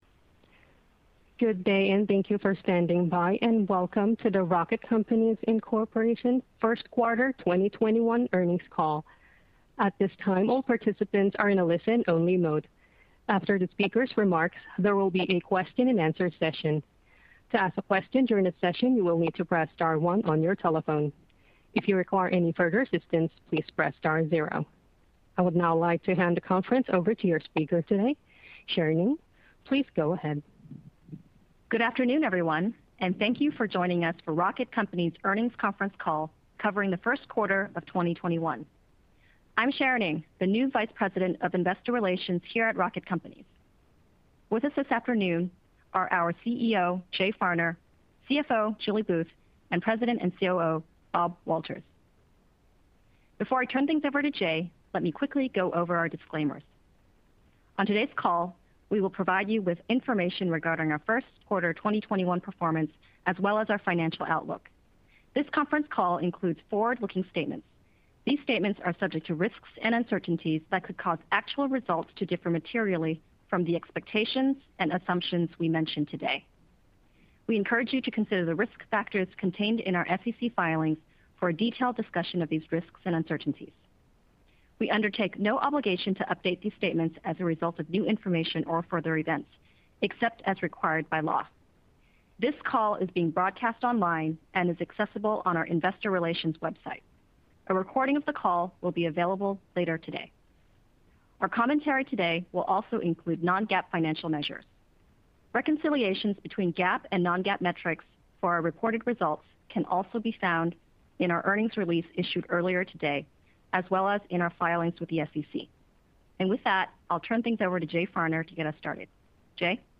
Rocket Companies, Inc. - Rocket Companies First Quarter 2021 Earnings Call
RocketCompanies_Q12021_EarningsCall.mp3